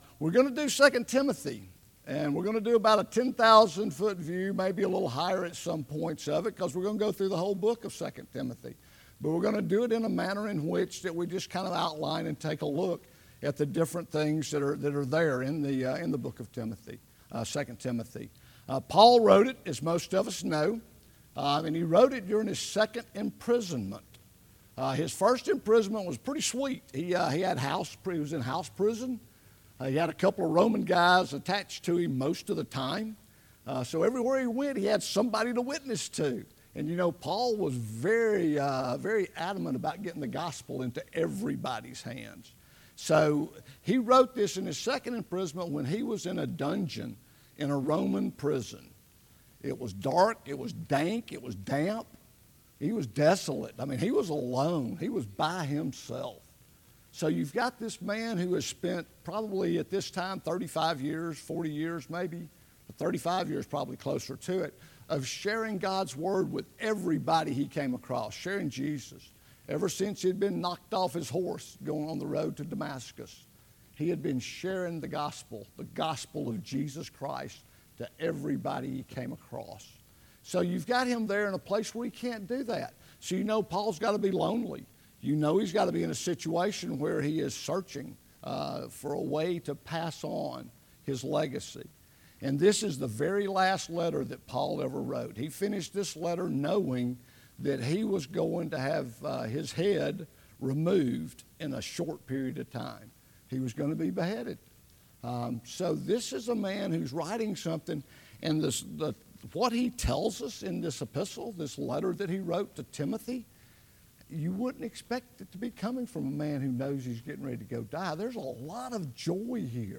Sermons | Gainesville Bible Church